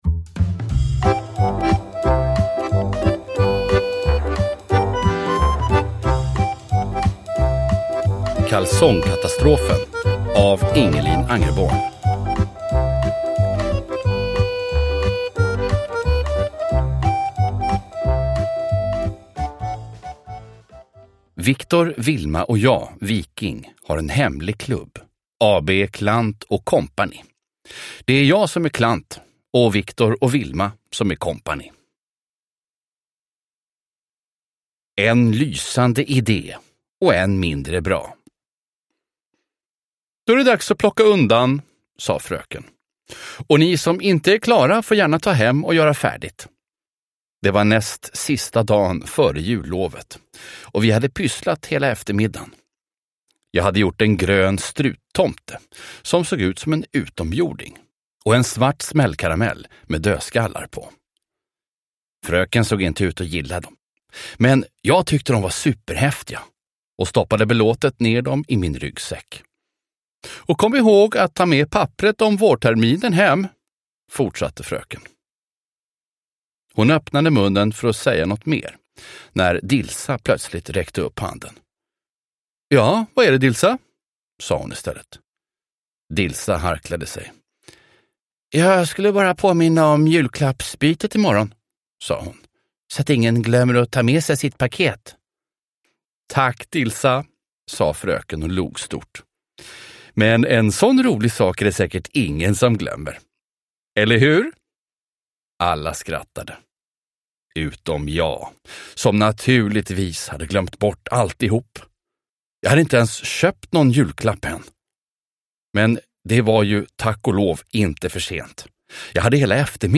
Kalsongkatastrofen – Ljudbok – Laddas ner